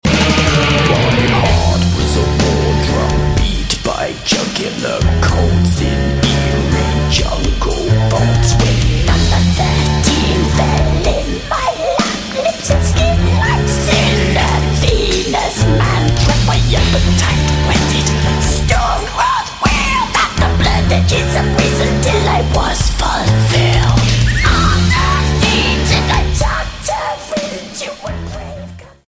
Category Pop & Rock